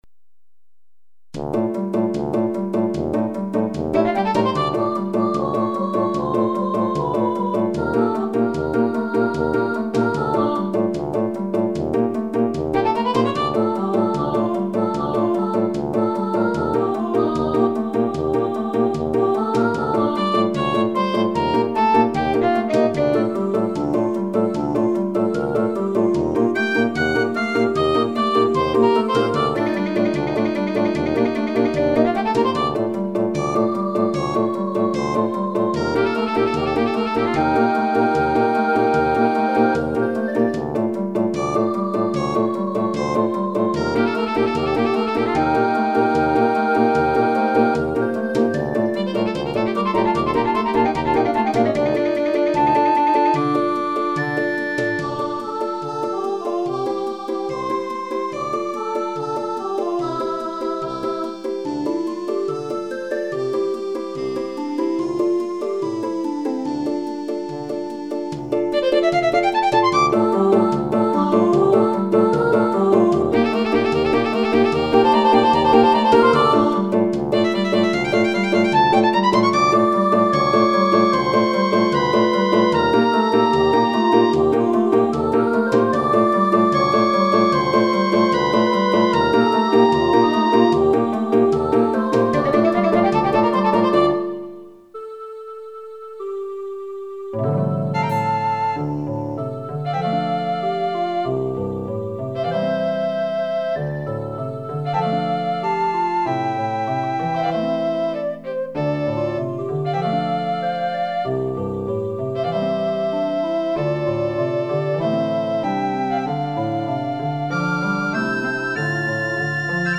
●合唱組曲「IRUMAここから」 choral suite "IRUMA kokokara"
2006年版ではフル編成の吹奏楽にアレンジし直しましたが、ここにあるファイルは初演のときのアンサンブル伴奏になっています。
Finaleで書いた譜面を鳴らしただけのチープな音です。
2 真実の愛（カラオケ）